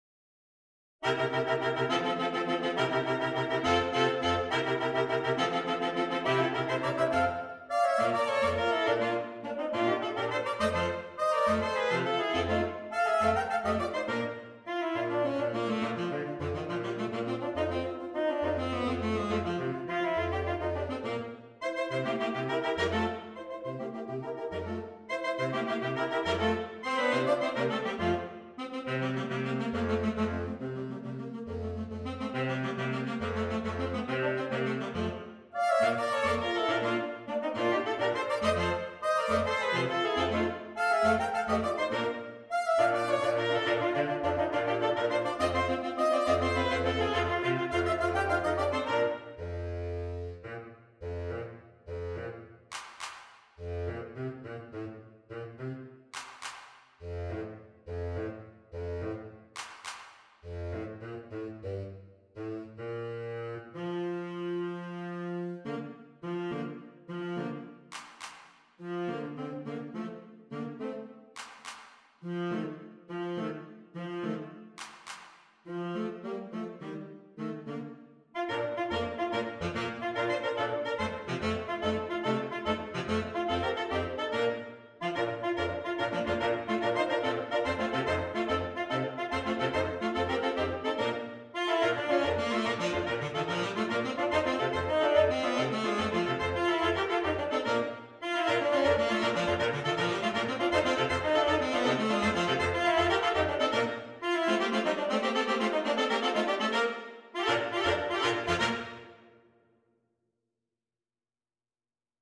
Saxophone Quartet (AATB)
Traditional
Fun, fun, fun!